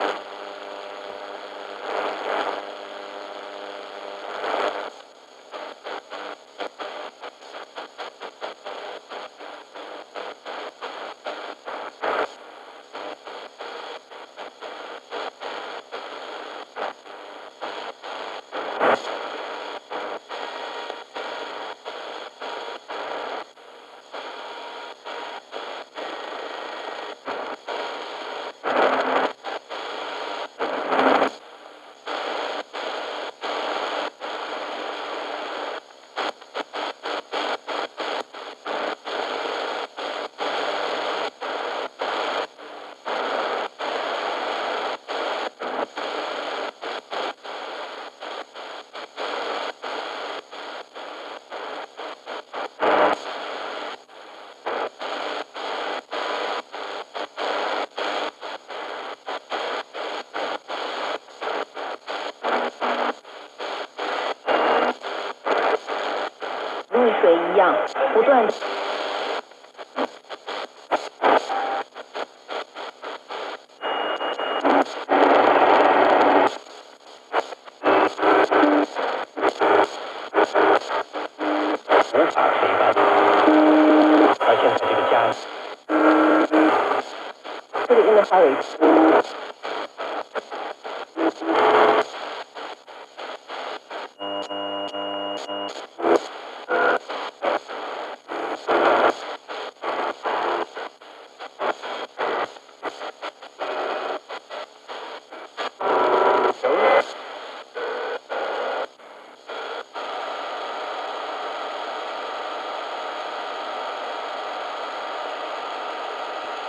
Radio tunning HQ
broadcast crackle frequency hiss noise radio range scale sound effect free sound royalty free Sound Effects